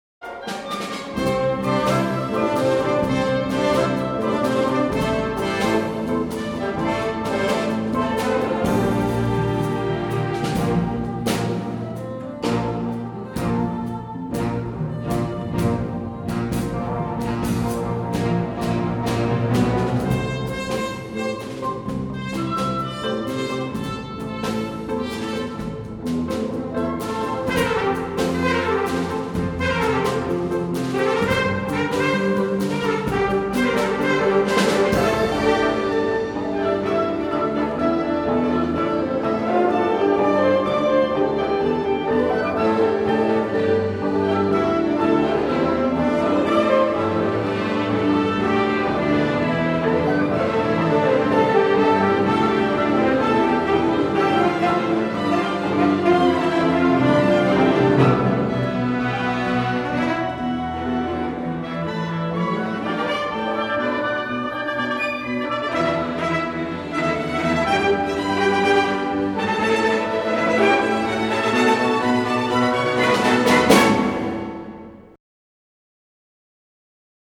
Orchestral Excerpt